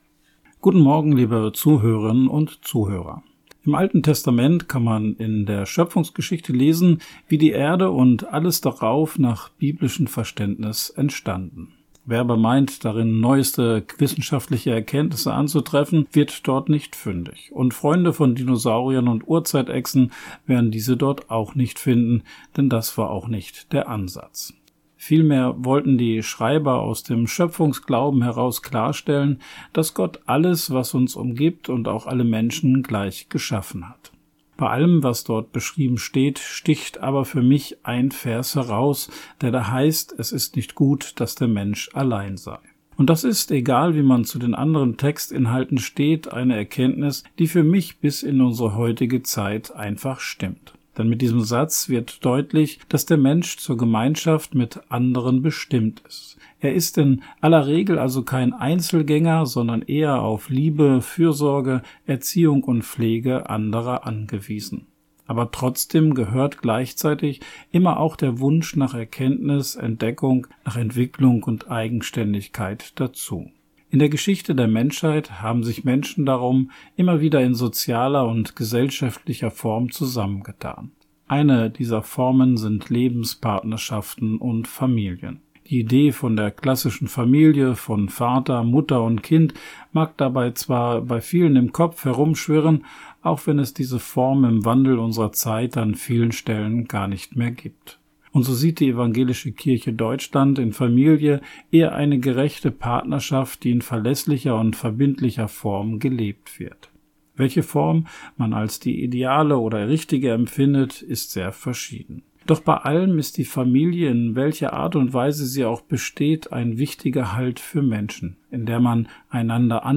Radioandacht vom 30. Dezember